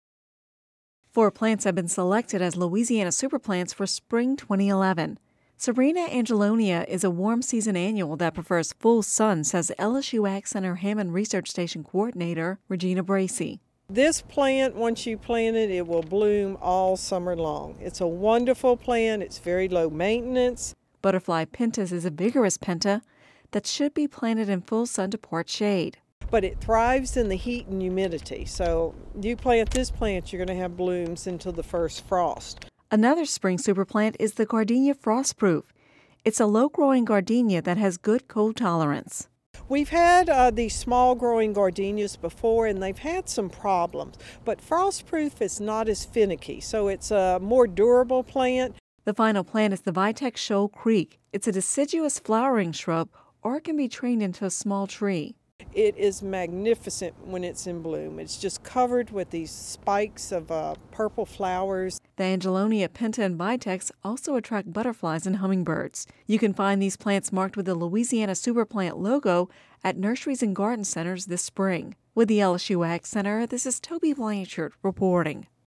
(Radio News 02/21/11) Four plants have been selected as Louisiana Super Plants for spring 2011.